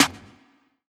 MZ Snare [Weeknder].wav